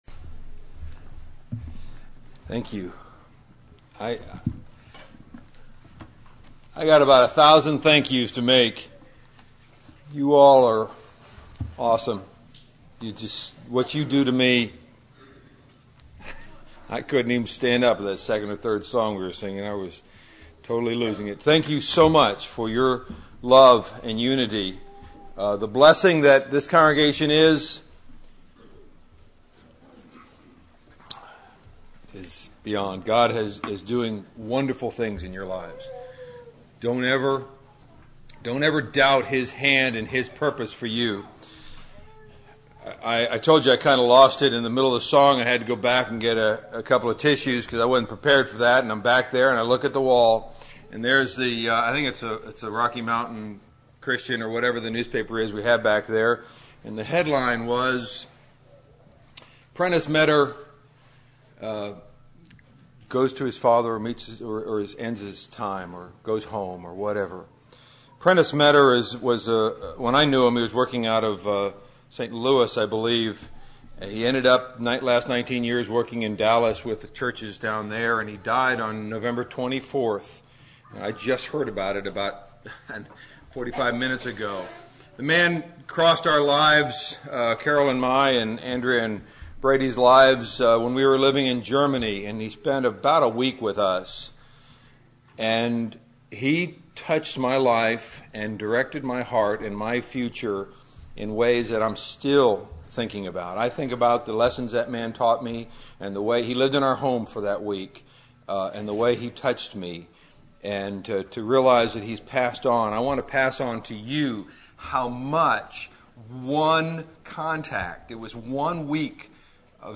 Valley church of Christ - Matanuska-Susitna Valley Alaska
Audio Sermons